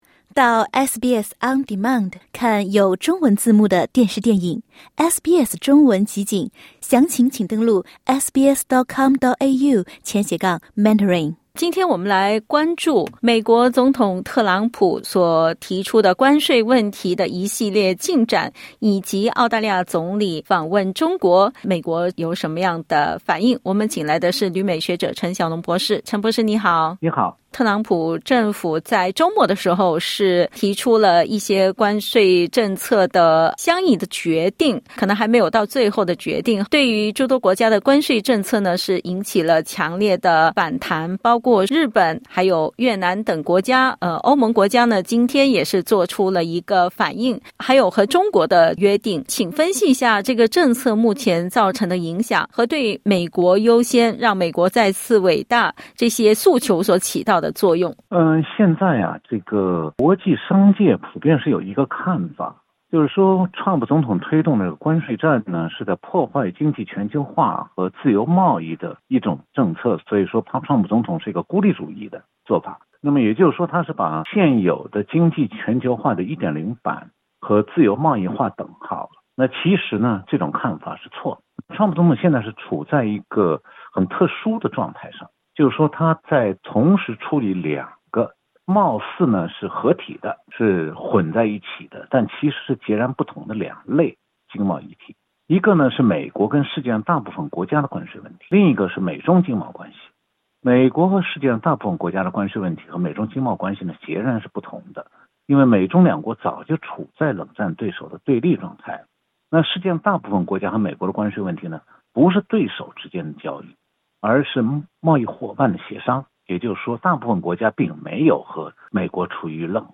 (点击音频收听详细内容） 采访内容仅为嘉宾观点 欢迎下载应用程序SBS Audio，订阅Mandarin。